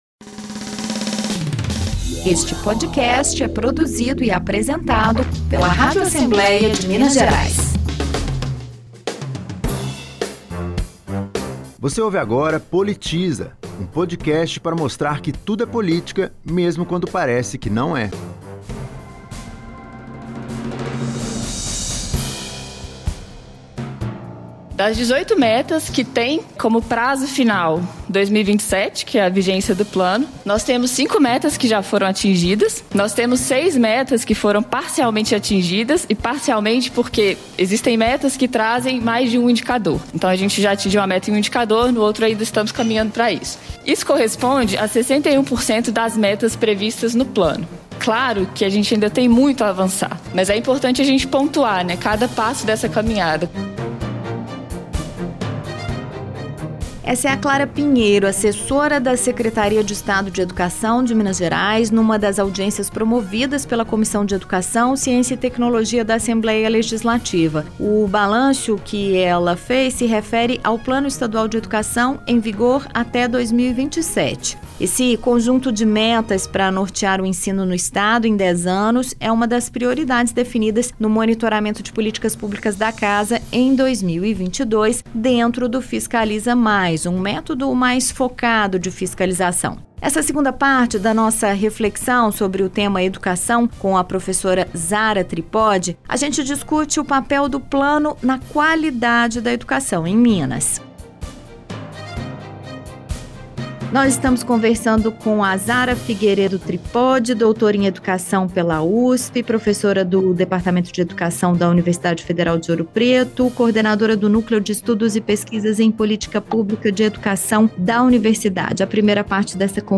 Saiba tudo nesta segunda parte do episódio especial sobre as políticas públicas de educação. Entrevistada